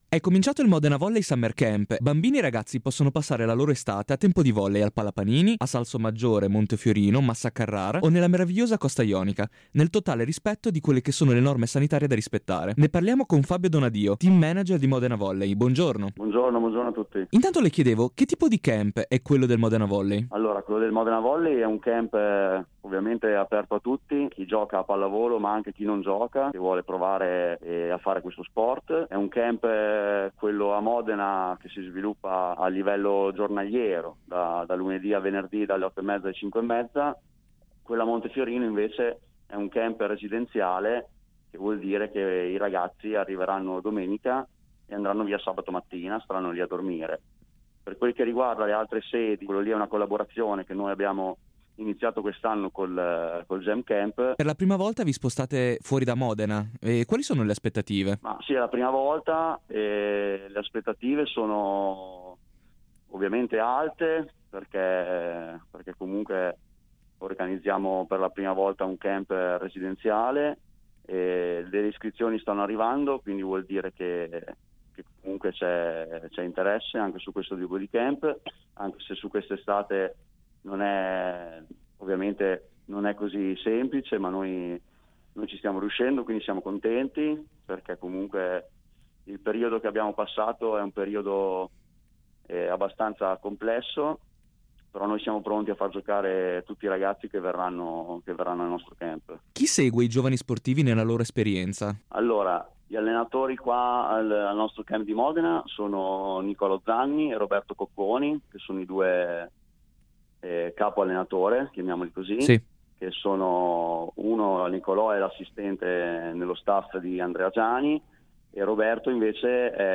che ha risposto così alle nostre domande: